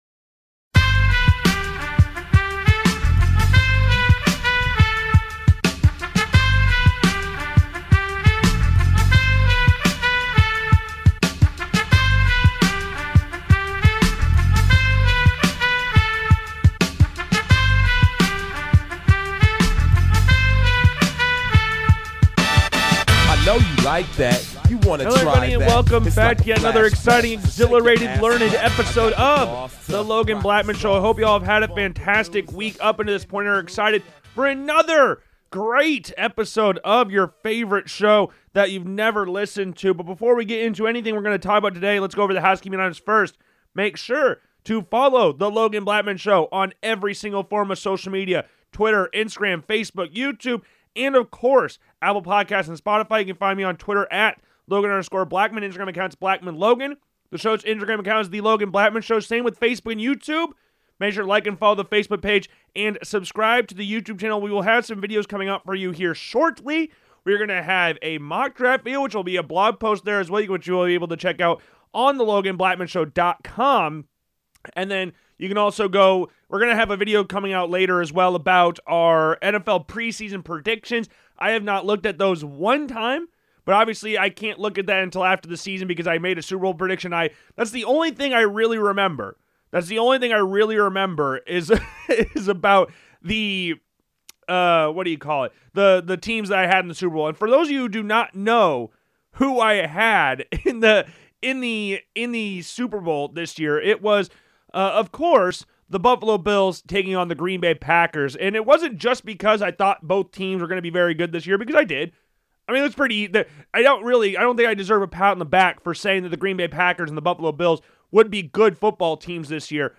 Be a guest on this podcast Language: en Genres: Comedy , Sports Contact email: Get it Feed URL: Get it iTunes ID: Get it Get all podcast data Listen Now...